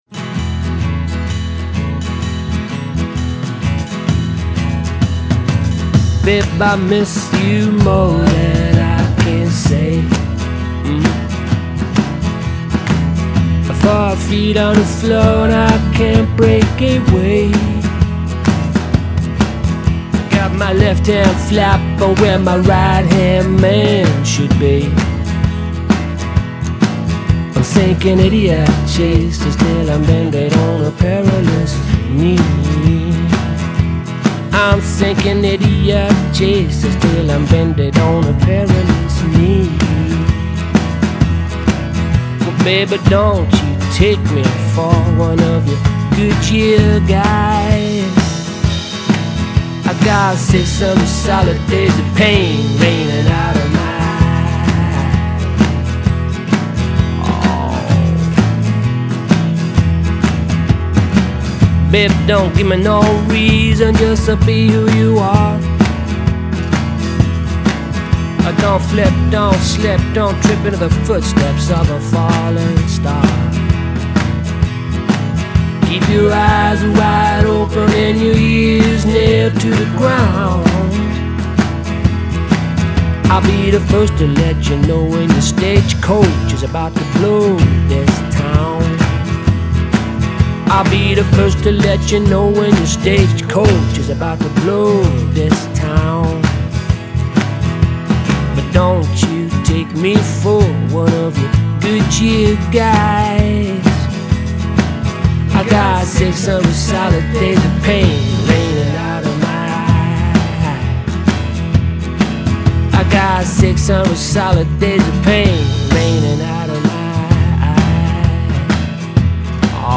• Blues
• Sänger/in